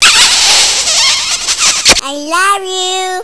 And a very cute sound (so what if it's not anime? ;P)
69.9 KB wav file (turn down your speakers a bit. This is kinda loud)